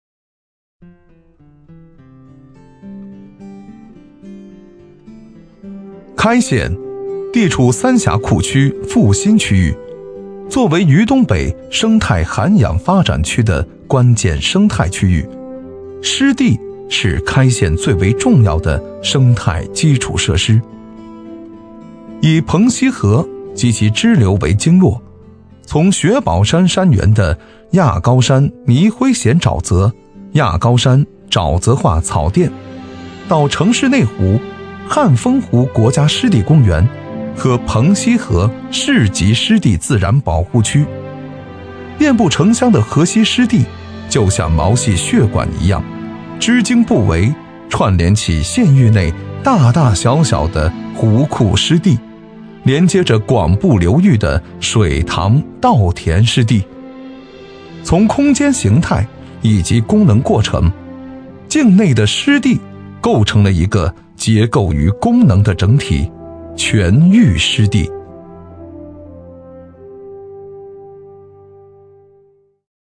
【男50号专题】开县湿地
【男50号专题】开县湿地.mp3